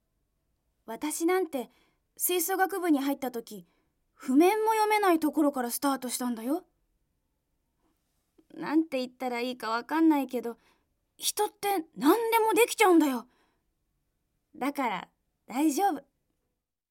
セリフ@
ボイスサンプル